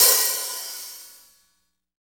HAT REAL H09.wav